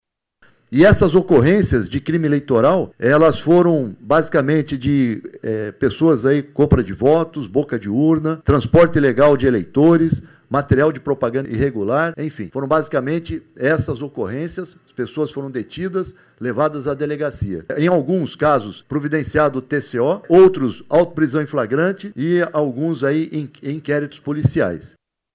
Durante coletiva de imprensa, órgãos envolvidos nas Eleições 2022 divulgaram dados.
Sonora-general-Mansour-–-secretario-da-SSP.mp3